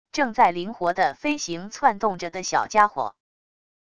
正在灵活的飞行窜动着的小家伙wav音频